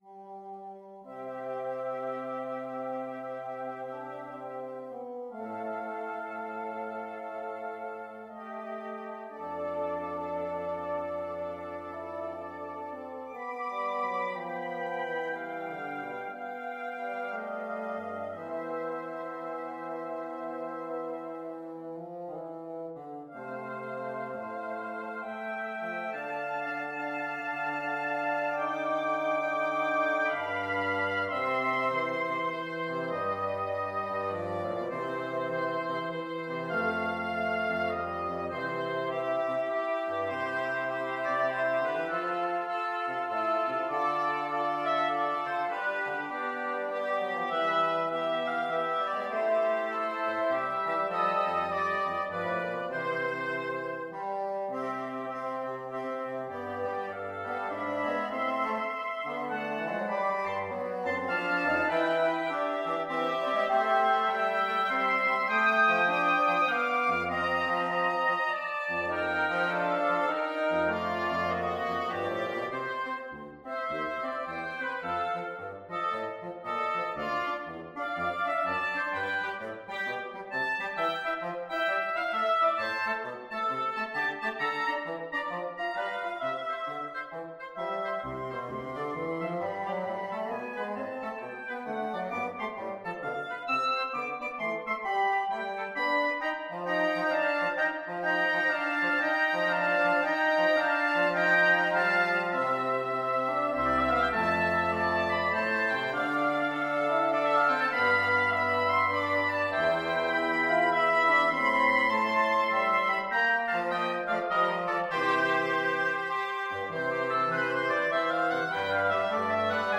Oboe 1Oboe 2BassoonBassoon 2
Adagio (swung throughout) =c.60
4/4 (View more 4/4 Music)
Jazz (View more Jazz Wind Quartet Music)